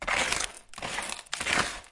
摇沙机
描述：在Zoom H4n上用接触式麦克风录制。
标签： 音乐 仪器
声道立体声